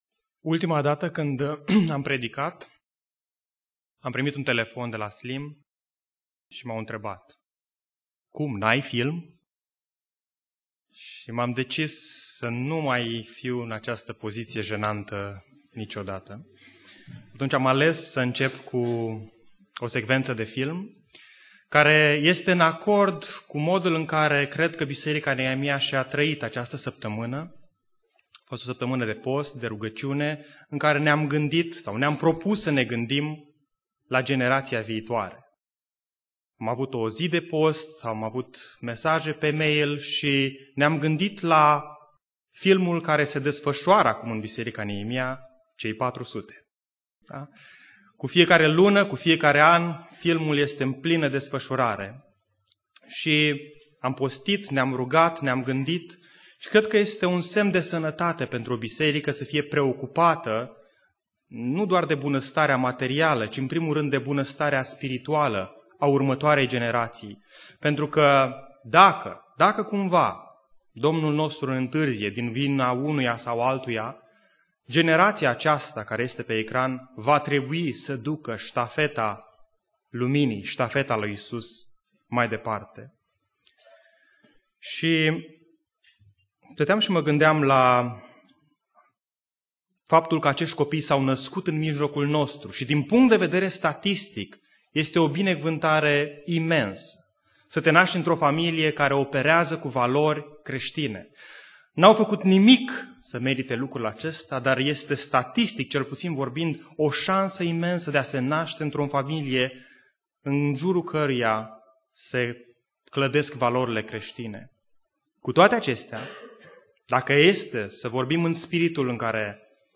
Predica Aplicatie - Matei 23b